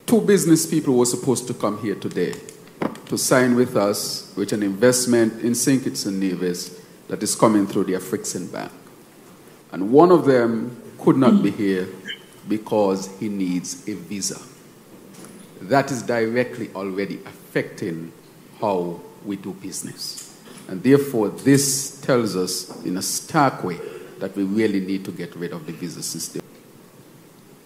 During a high-level discussion between African and fellow Caribbean leaders, PM Drew, advocated for the removal of visa barriers which are present hinderances to developing collaboration in not only trade but other areas such as education.